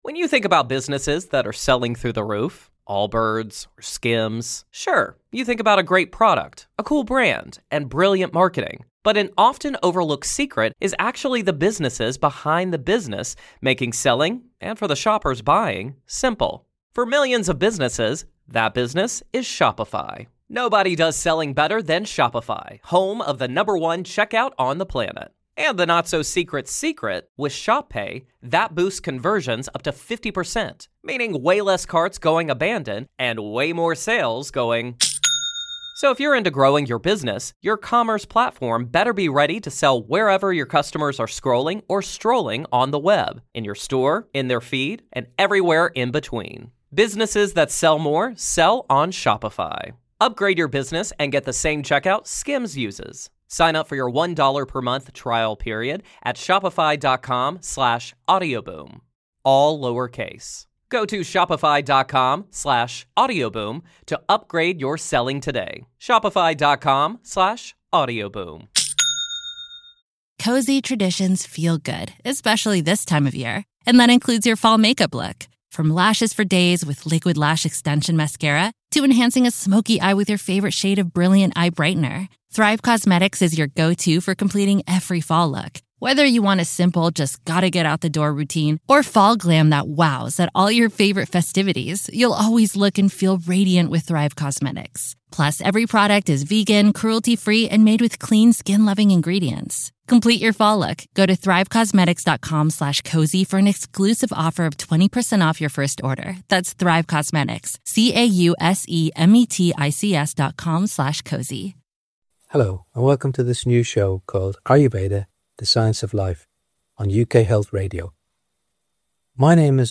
I'll interview therapists and health experts who share a holistic view of wellness. Ayurveda uses food and herbal remedies to address the root causes of illness, emphasising the link between physical symptoms and unresolved emotional issues.